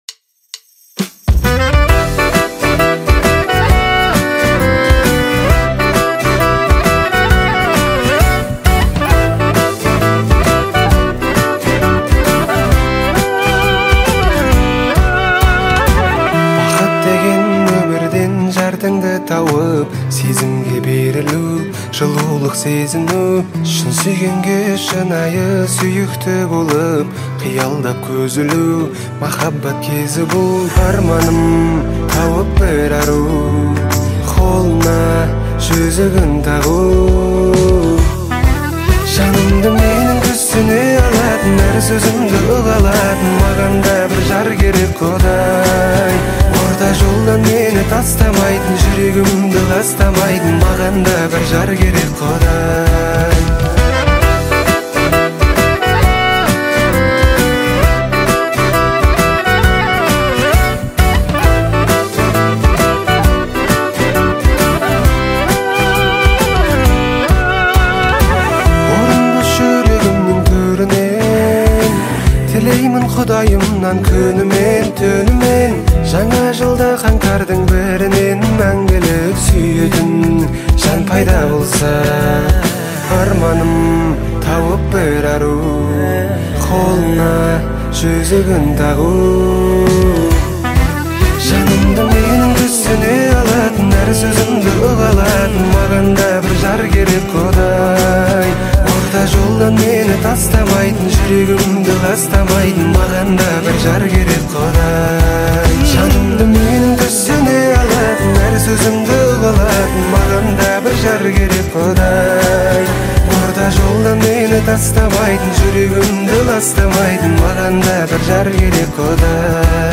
• Категория: Казахские песни